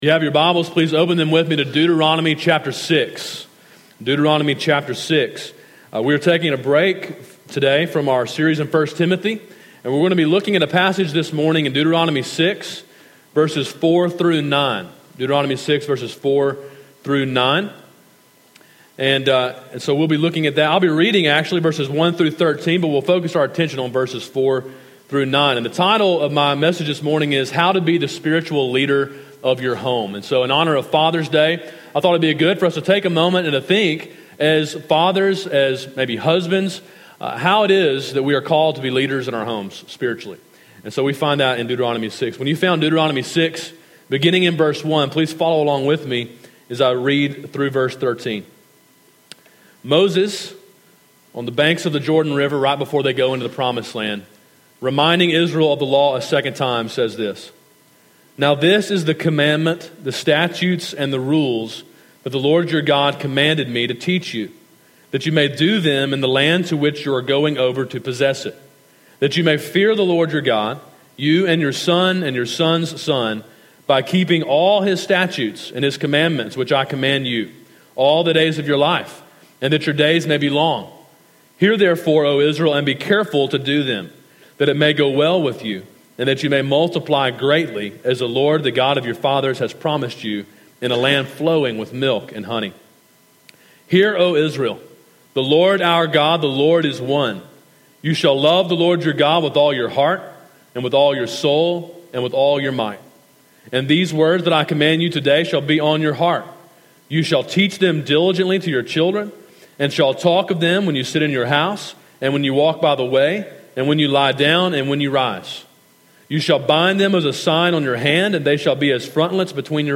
A Father’s Day sermon preached on June 19, 2016.